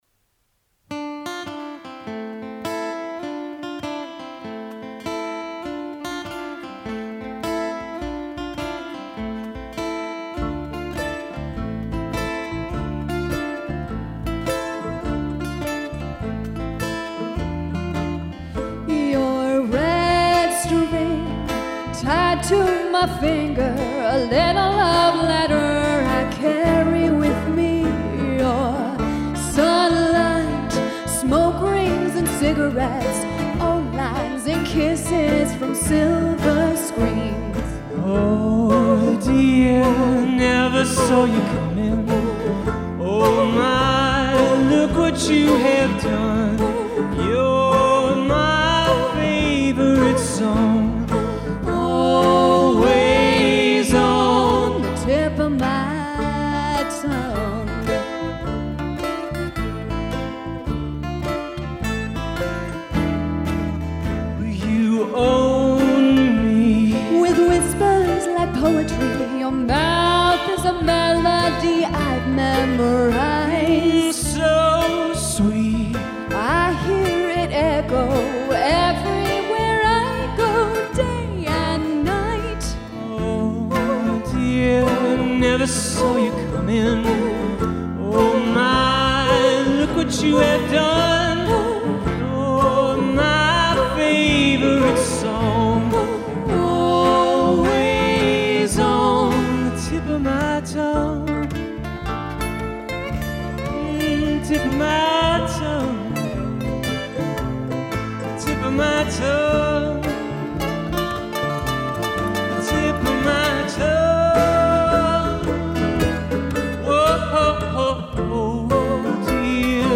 TIP OF MY TONGUE  (The Civil Wars) Performed by The Back Porch Project band on January 2017 at the Ukiah United Methodist Church, Ukiah Unplugged Concert Series.
mandolin
guitar and vocals
percussion